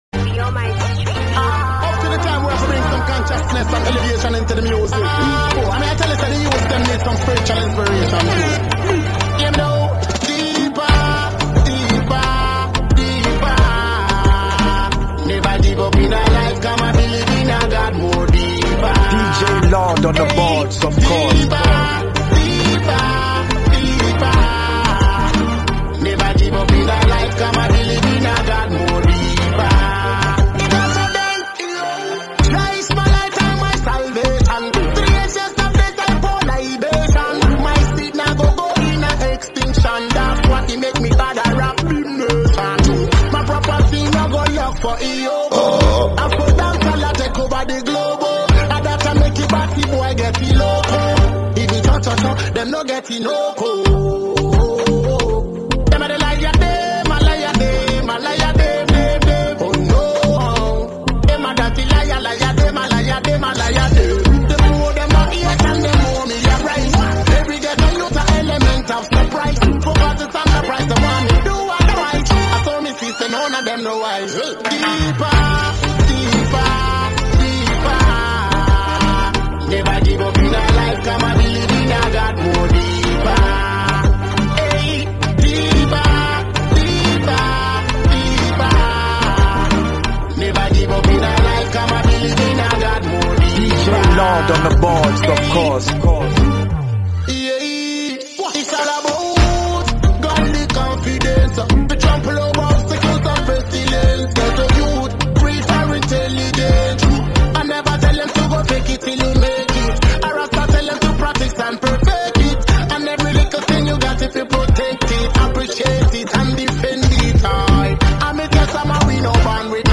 dancehall
Genre: Mixtape
blends fan favorites and classic hits
” now and enjoy the vibrant sounds of Ghana!